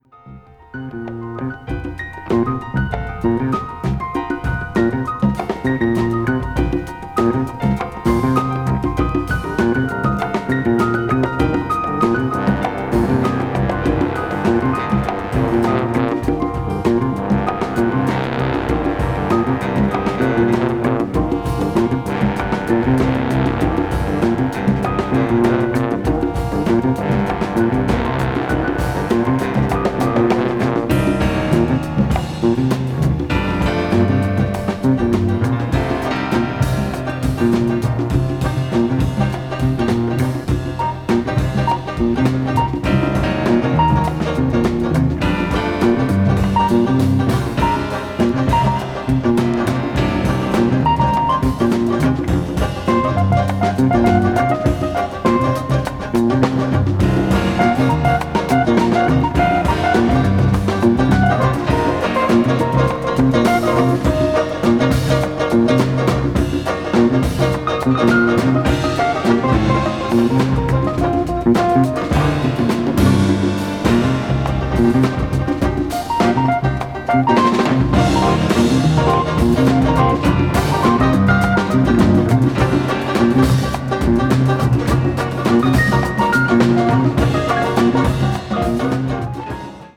A Composition for Jazz Orchestra
media : EX+/EX+(some slightly noises.)
baritone saxophone